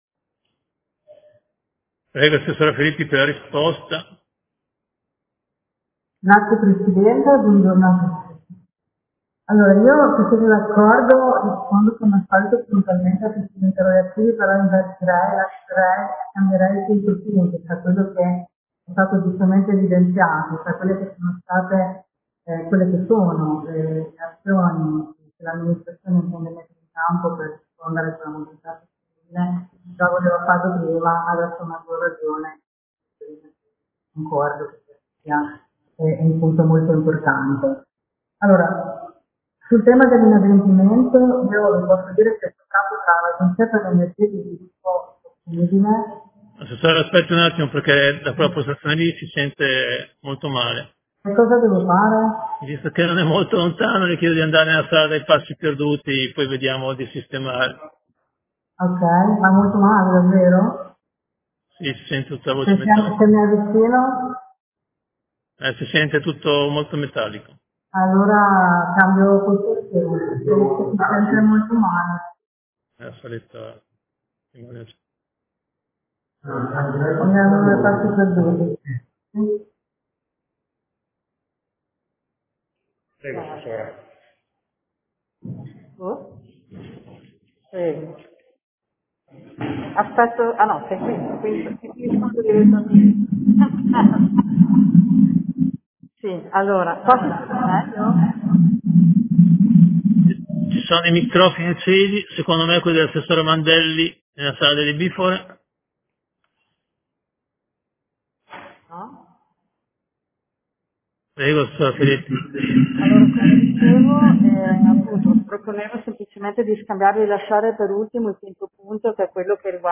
Alessandra Filippi — Sito Audio Consiglio Comunale